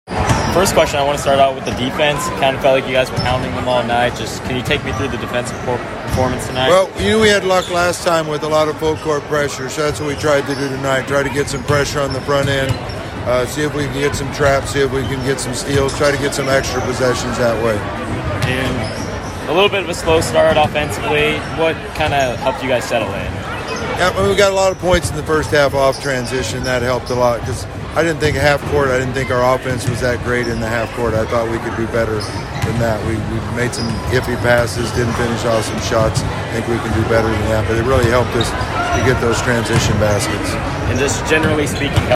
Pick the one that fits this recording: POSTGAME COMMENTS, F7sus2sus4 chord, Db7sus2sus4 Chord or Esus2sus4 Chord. POSTGAME COMMENTS